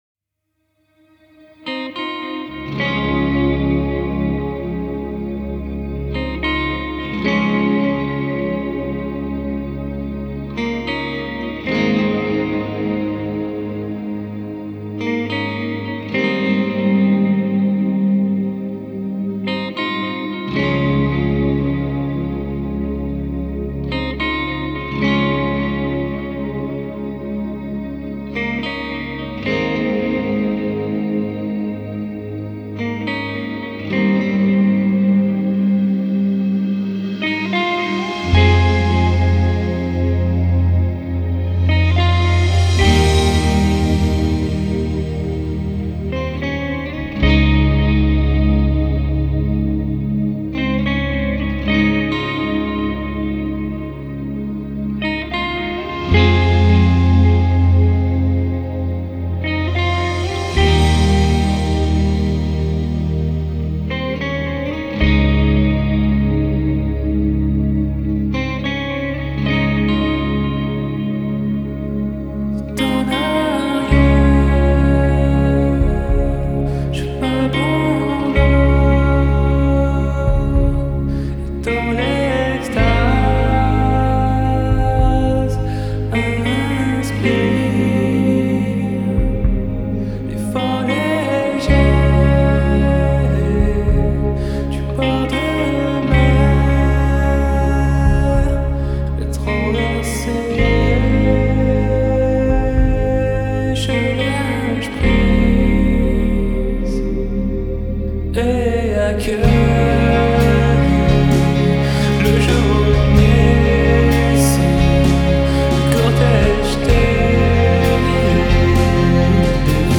French shoegazers